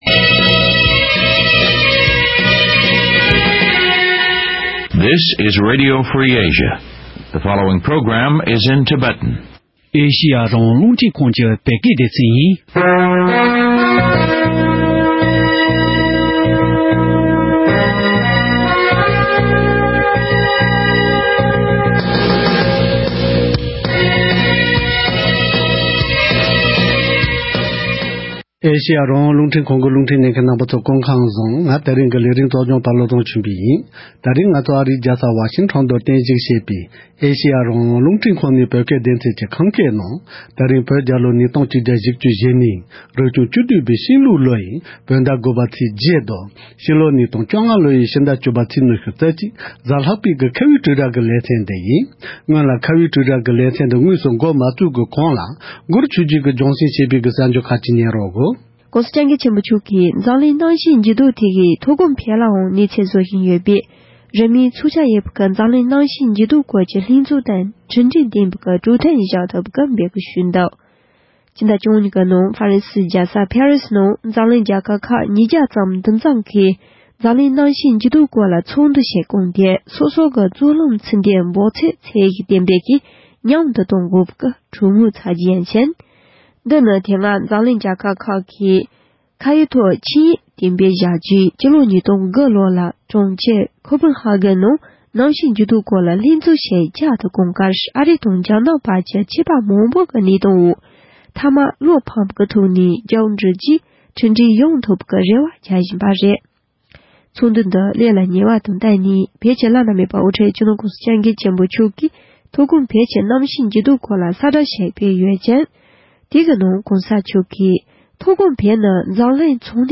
ད་རེས་འོས་བསྡུ་དེ་ས་གནས་སོ་སོའི་ནང་ལ་ཅི་འདྲ་བྱུང་ཡོད་མིན་སོགས་སྐོར་གླེང་མོལ་ཞུས་པ་ཞིག་གསན་རོགས་གནང་།